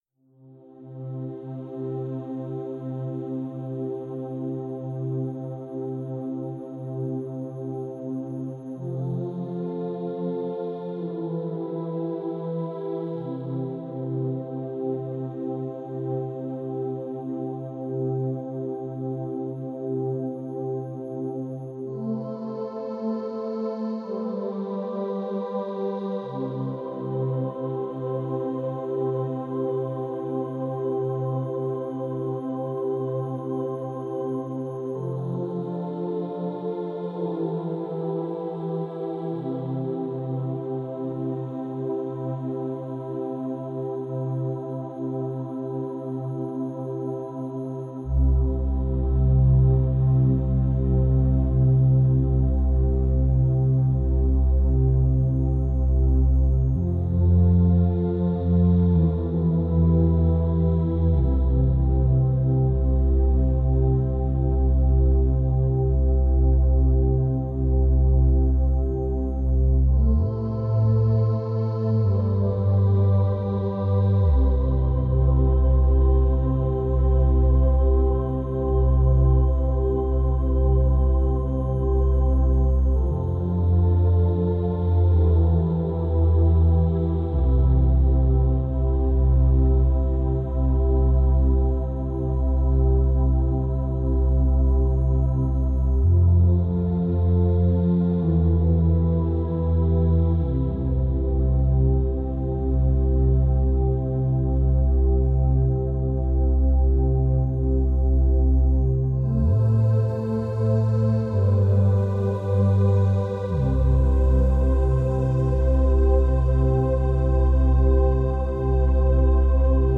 4hz - Theta Binaural Beats for Daydreaming ~ Binaural Beats Meditation for Sleep Podcast